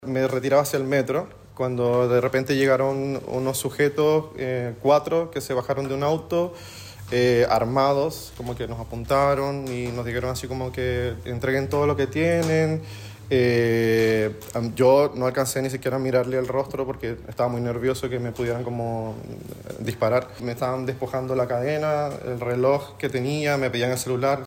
Una de las víctimas sostuvo que fue abordado por cuatro personas mientras se dirigía al Metro Cerro Blanco, quienes lo intimidaron con armas de fuego.
cu-trasnoche-2-victima.mp3